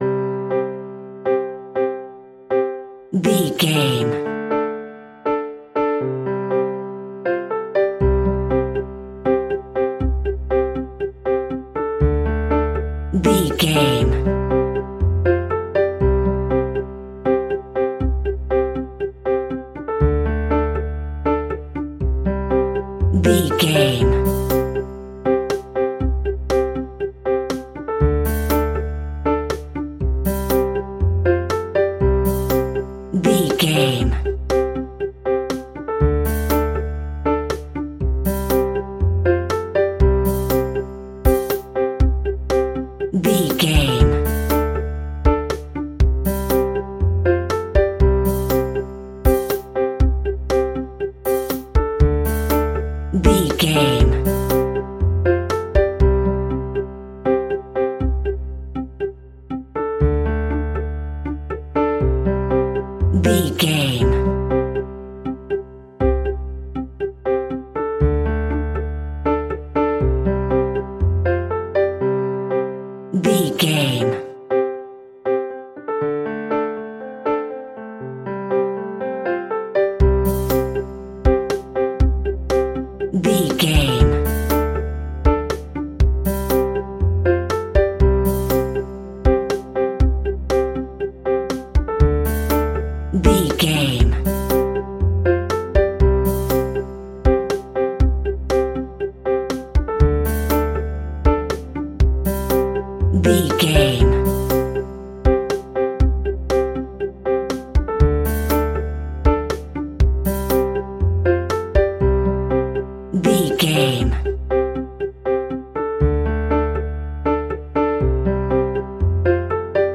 Ionian/Major
childrens music
instrumentals
fun
childlike
cute
happy
kids piano